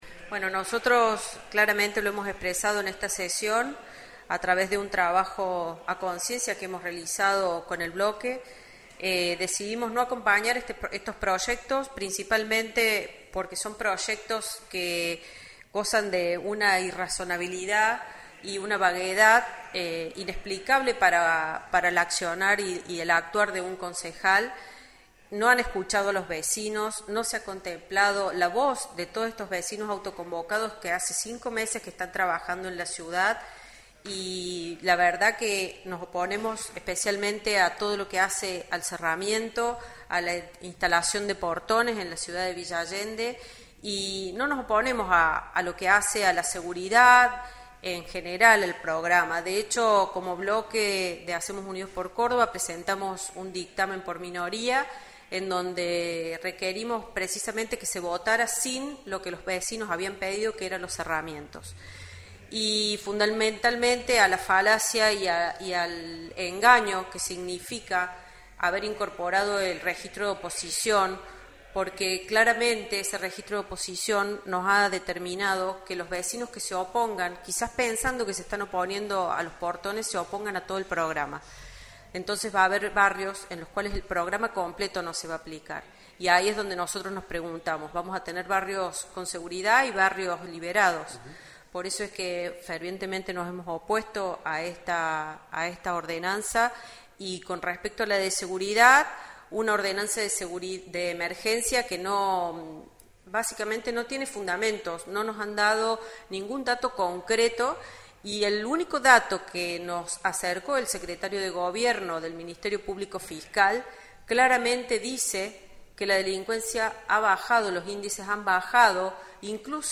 ENTREVISTA A PATRICIA HAMITY, CONCEJALA DE HACEMOS UNIDOS POR VILLA ALLENDE